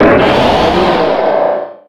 Cri de Méga-Jungko dans Pokémon Rubis Oméga et Saphir Alpha.